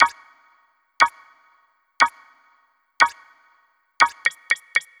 5_sec_countdown.ogg